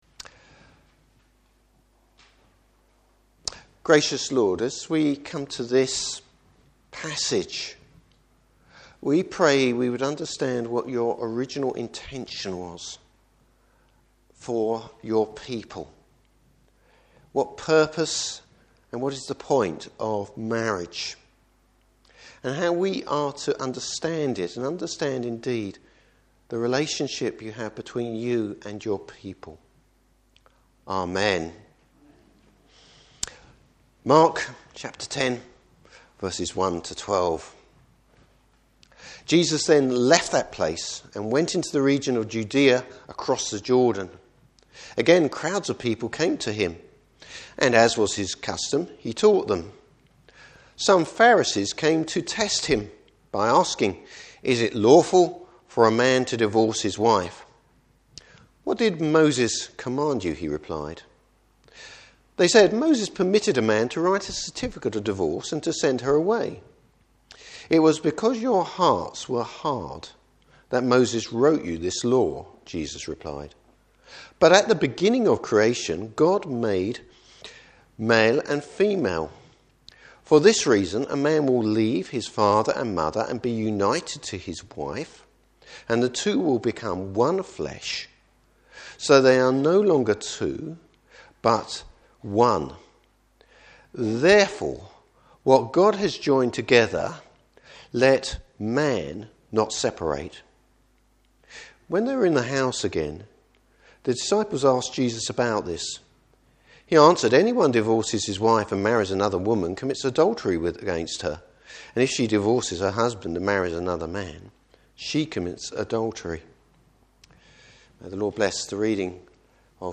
Service Type: Morning Service What was God’s original intention for marriage?